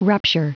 Prononciation du mot rupture en anglais (fichier audio)
Prononciation du mot : rupture